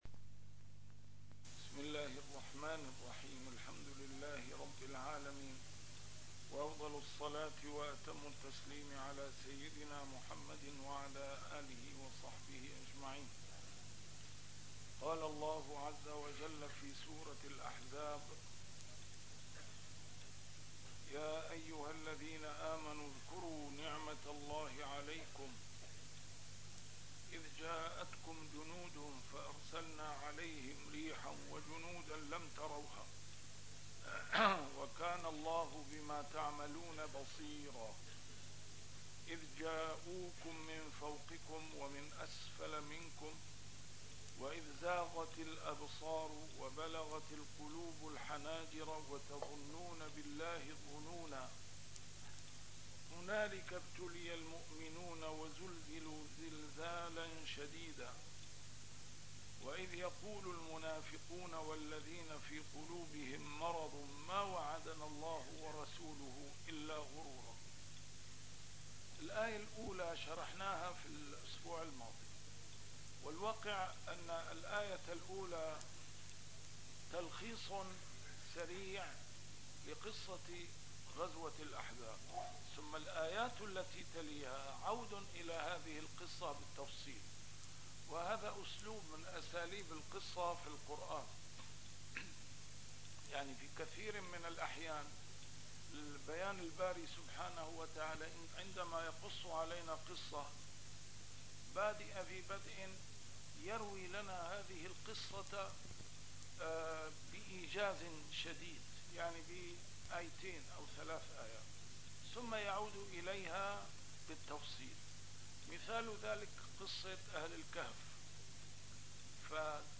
A MARTYR SCHOLAR: IMAM MUHAMMAD SAEED RAMADAN AL-BOUTI - الدروس العلمية - تفسير القرآن الكريم - تسجيل قديم - الدرس 365: الأحزاب 10-12